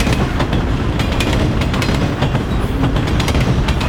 train-sound